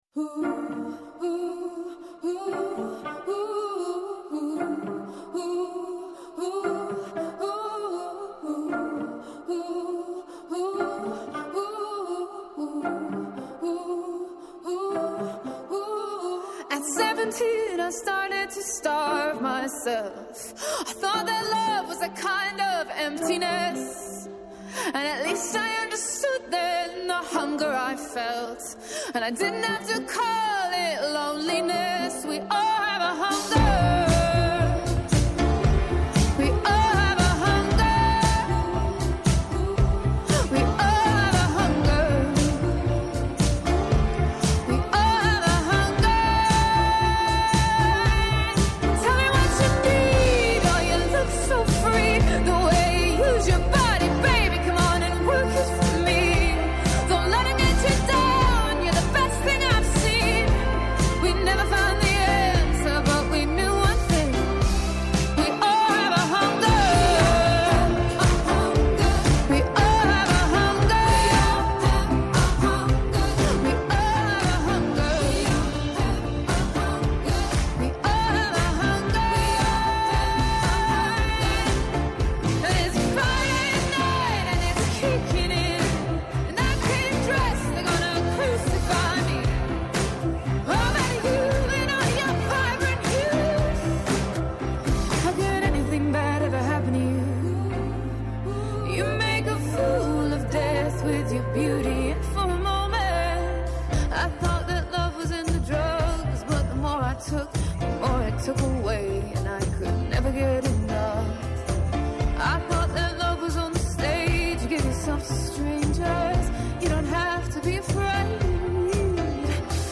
προσεγγίζει τα μεγάλα θέματα της πρώτης γραμμής με καλεσμένους, ρεπορτάζ και σχόλια.